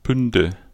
Ääntäminen
Ääntäminen Tuntematon aksentti: IPA: /ˈbʏndə/ Haettu sana löytyi näillä lähdekielillä: saksa Käännöksiä ei löytynyt valitulle kohdekielelle. Bünde on sanan Bund monikko.